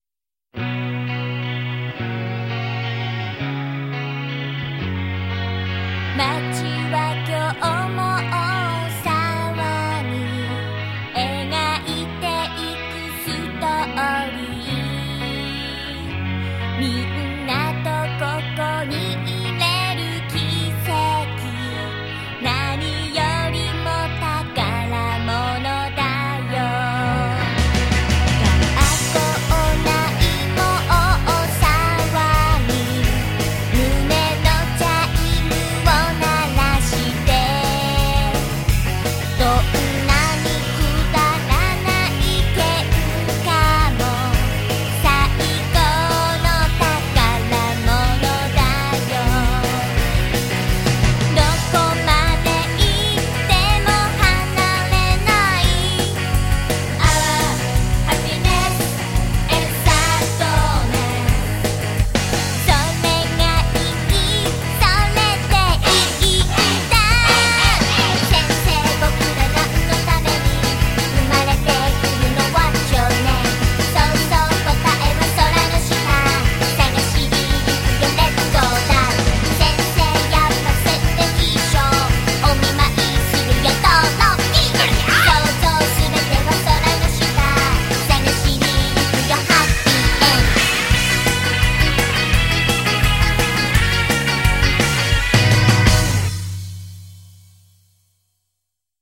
normalized to -0dB